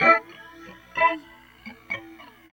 71 GTR 1  -R.wav